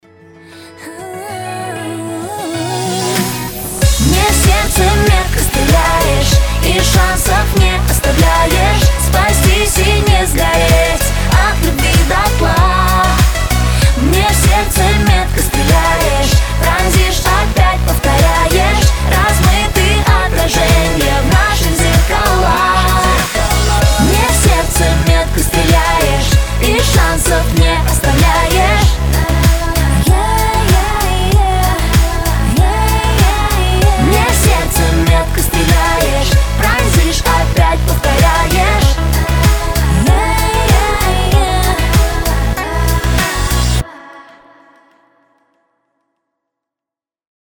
• Качество: 320, Stereo
романтичные
нежные